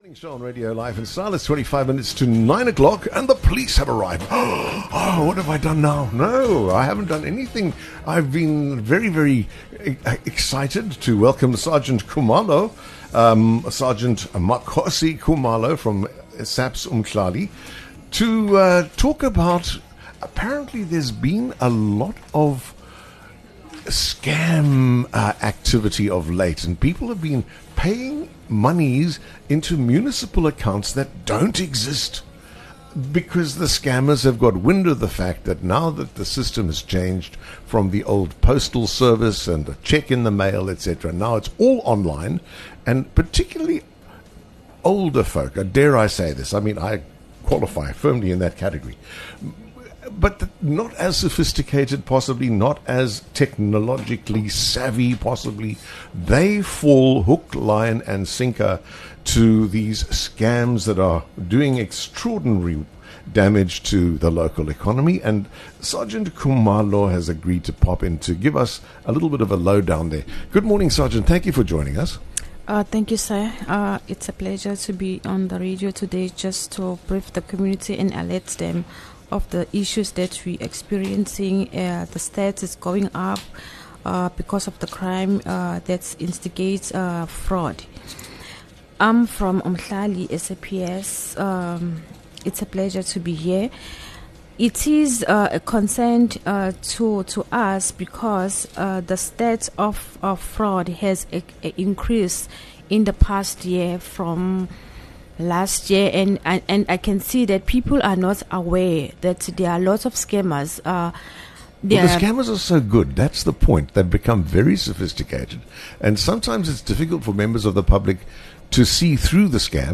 The interview focuses on the rise of scams involving fake municipal or metro bills being sent to homeowners, highlighting how to identify these scams and protect yourself.